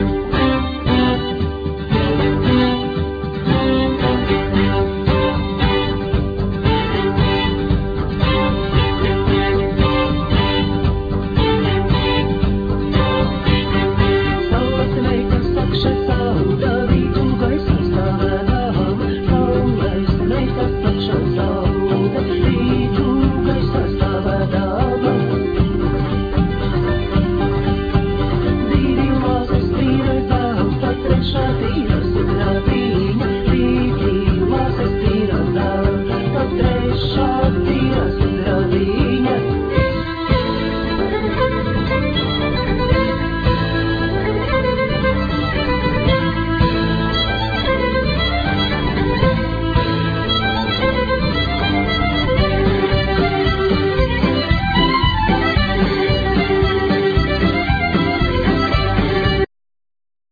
Vocal,Violin
Vocal,Kokle,Bagpipe,Acordeon
Vocal,Bass,Giga
Vocal,Guitar
Percussions